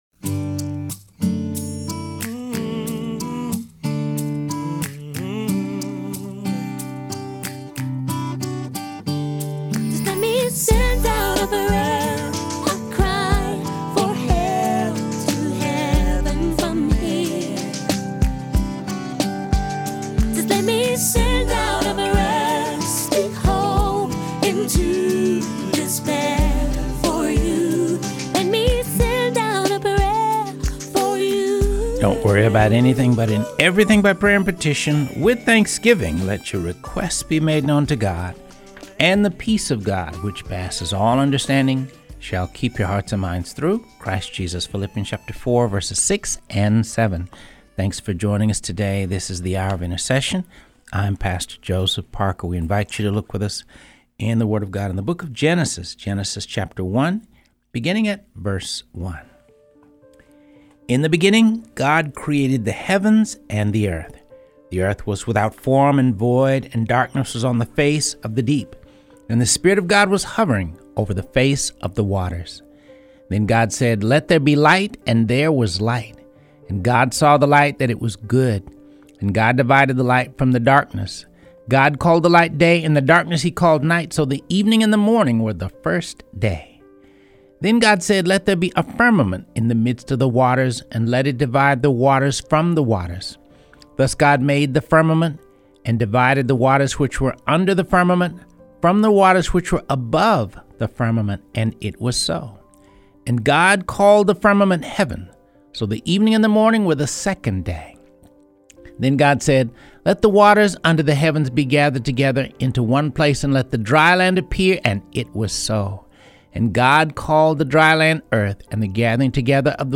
Reading through the Word of God, ep. 202